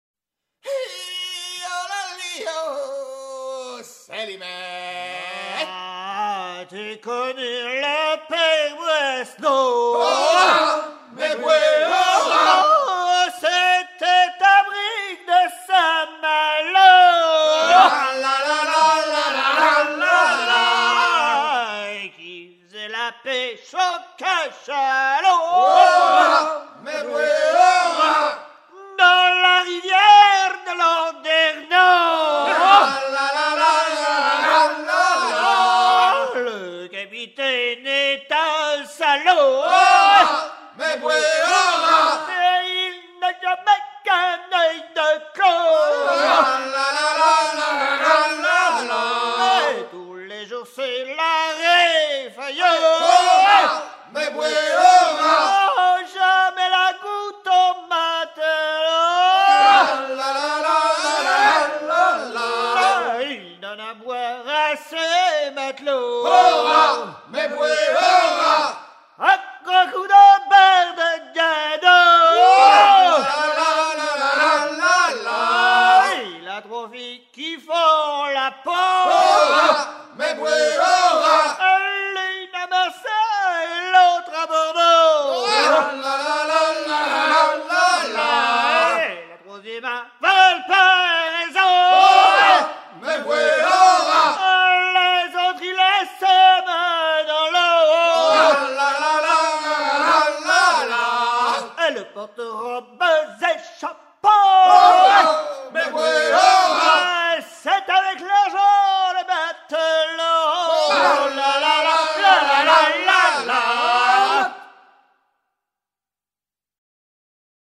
gestuel : à hisser main sur main
circonstance : maritimes
Genre laisse